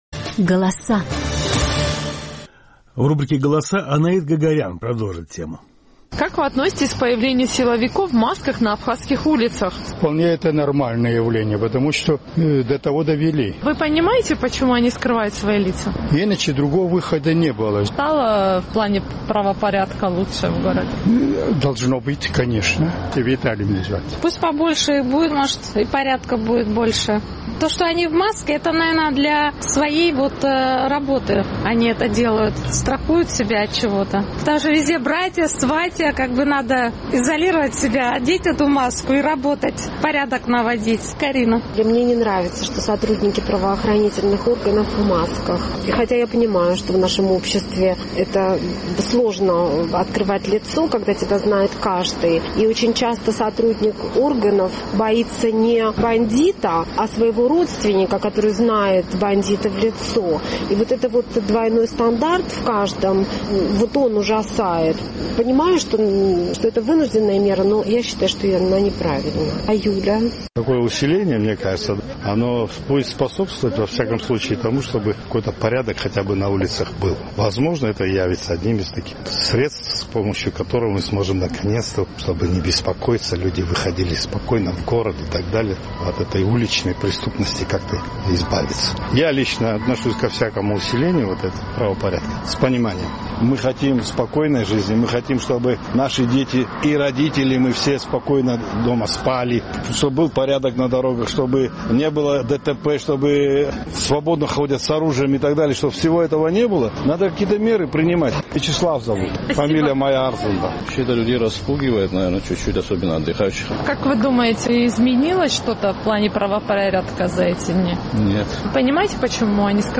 Наш сухумский корреспондент поинтересовалась у жителей абхазской столицы, как они относятся к появлению на улицах силовиков в масках.